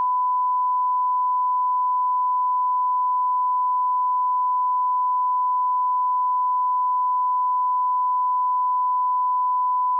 sine.wav